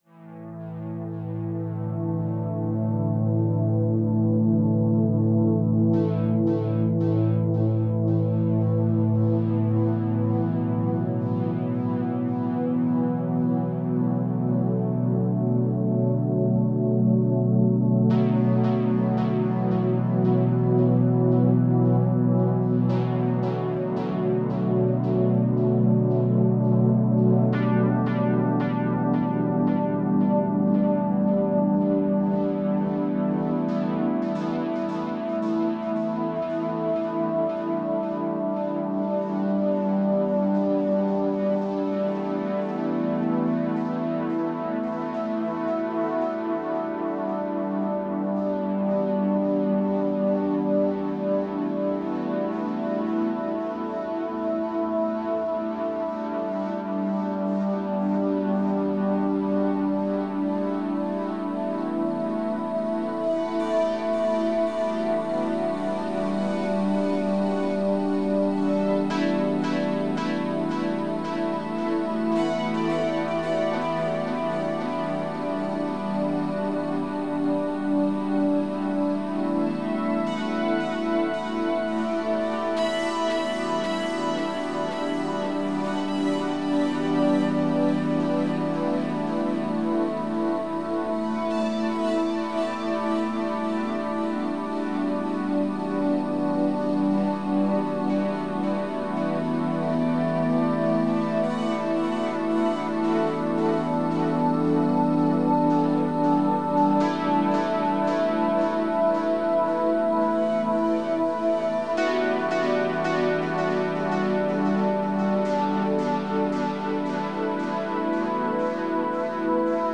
Genre: IDM, Ambient.